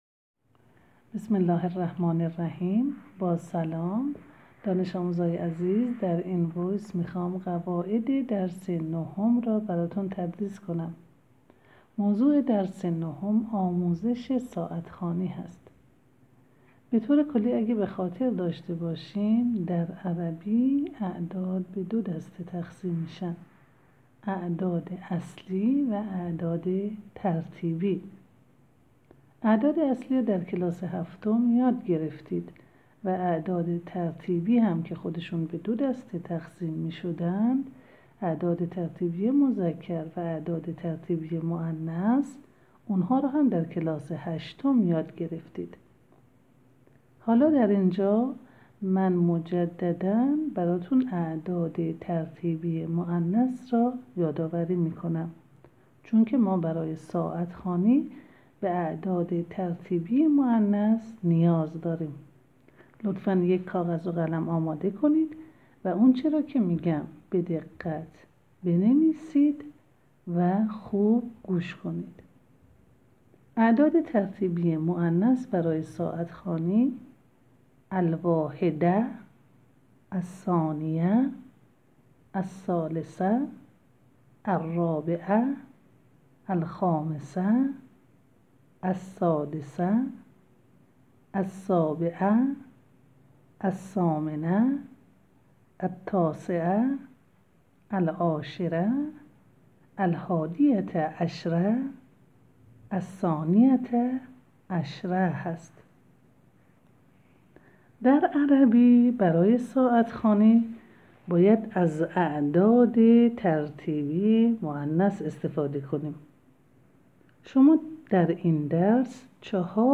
صوت تدریس قواعد درس ۹